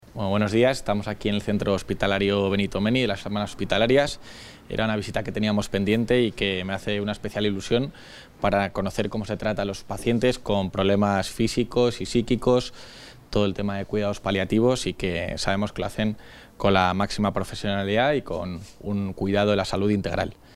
Material audiovisual de la visita del vicepresidente de la Junta al Centro Hospitalario P. Benito Menni
Audio vicepresidente.